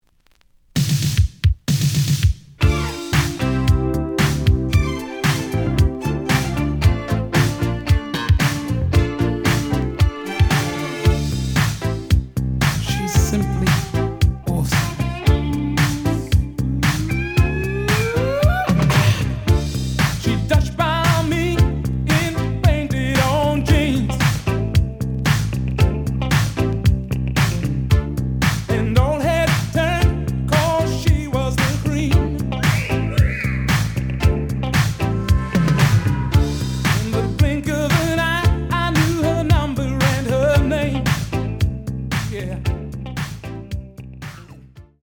The audio sample is recorded from the actual item.
●Genre: Funk, 80's / 90's Funk